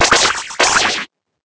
Cri d'Embrochet dans Pokémon Épée et Bouclier.